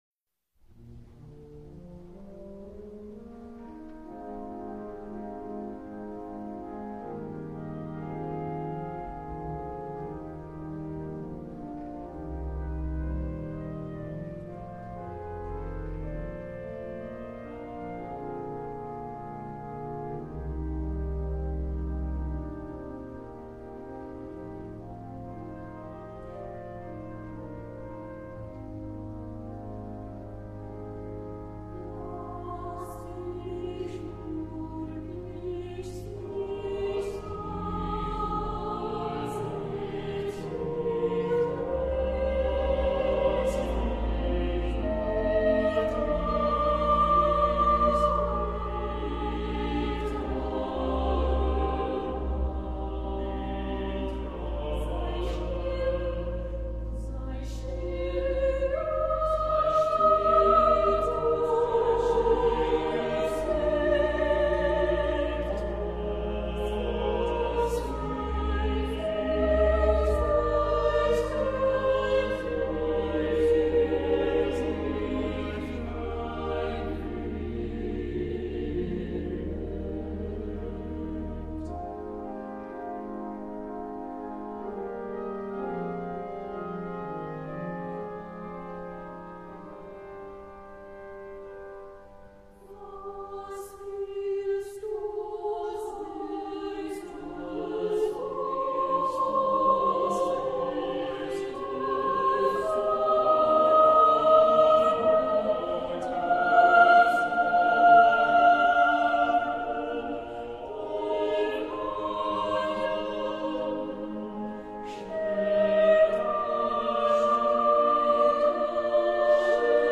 Accompaniment:      Organ
Music Category:      Choral
Solfege edition: Major mode.